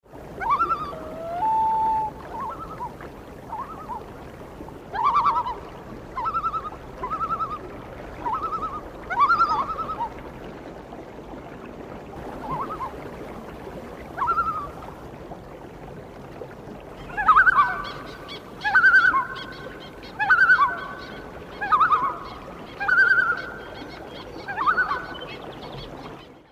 real-loons.mp3